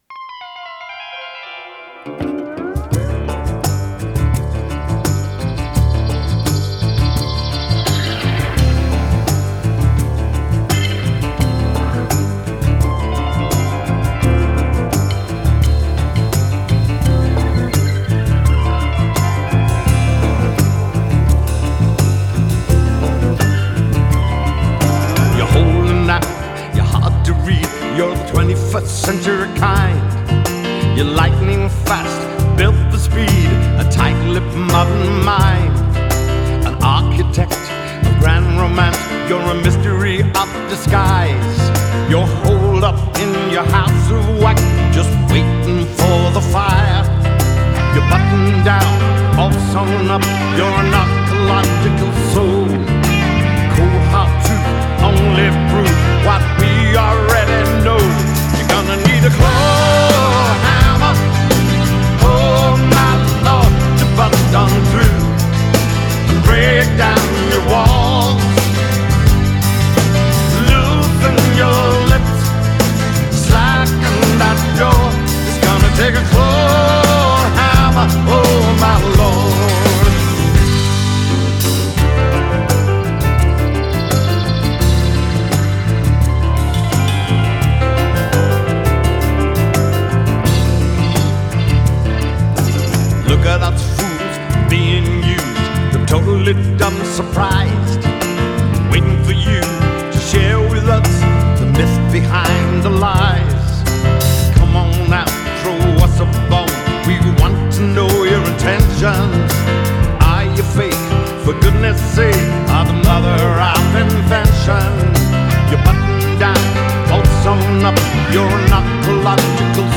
recorded at The Village in Los Angeles
Genre: Pop Rock, Classic Rock, Soft Rock